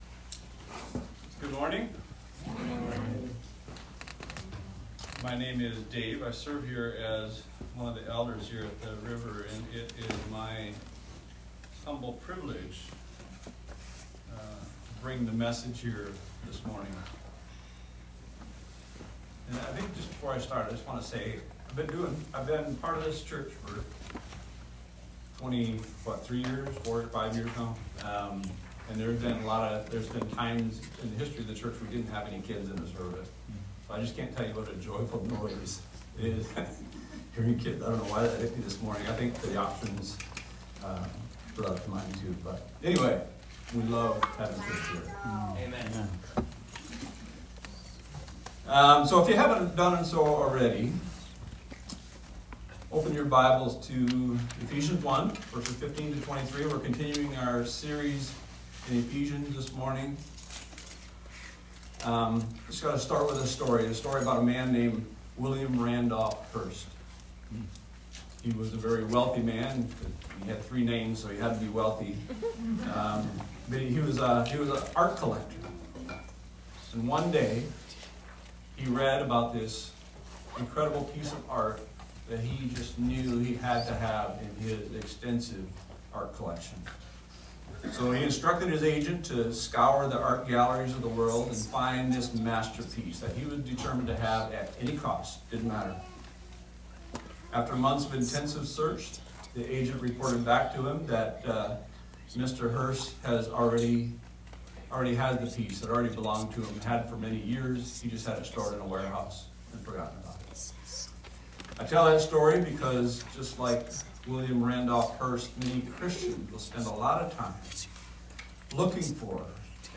This is a recording of a sermon titled, "Paul's Prayer of Hope."